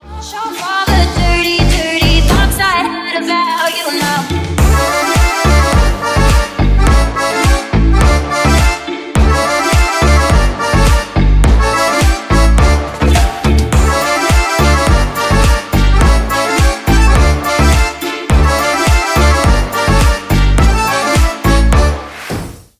Ремикс # Поп Музыка
клубные